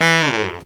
Index of /90_sSampleCDs/Best Service ProSamples vol.25 - Pop & Funk Brass [AKAI] 1CD/Partition C/BARITONE FX2